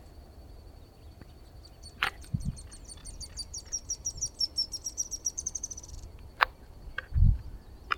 Straight-billed Reedhaunter (Limnoctites rectirostris)
Sex: Both
Life Stage: Adult
Location or protected area: Parque Nacional Ciervo de los Pantanos
Condition: Wild
Certainty: Photographed, Recorded vocal
Pajonalera-Pico-Recto.mp3